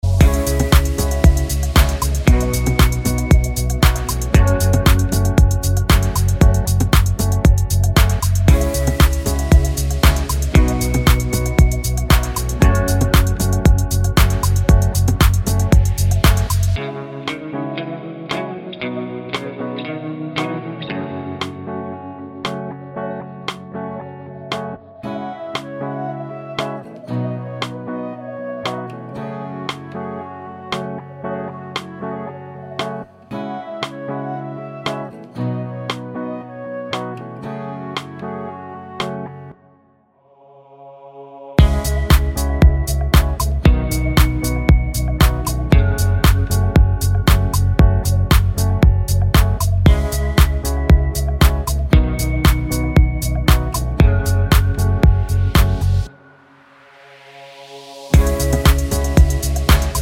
no Backing Vocals Finnish 3:08 Buy £1.50